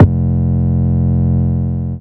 Index of /Antidote Advent/Drums - 808 Kicks
808 Kicks 02 F.wav